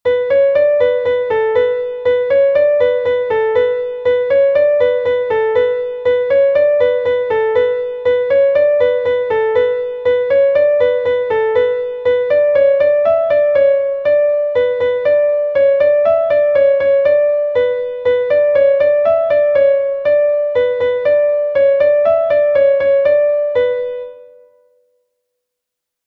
Rond Traines Meuriennes II is a Rond from Brittany recorded 1 times by Traines Meuriennes